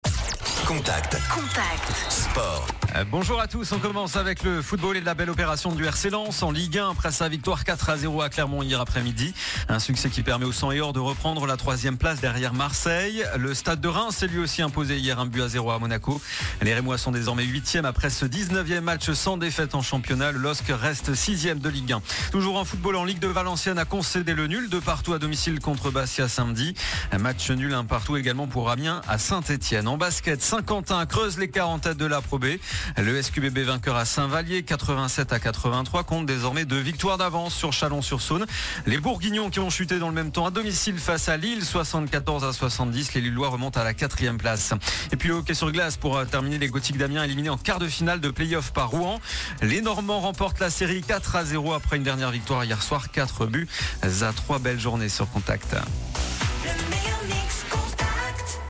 Le journal des sports du lundi 13 mars